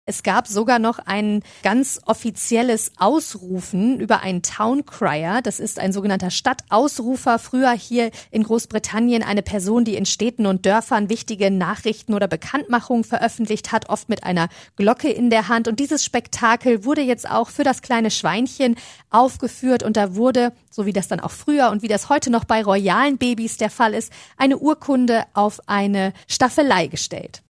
SWR3 Korrespondentin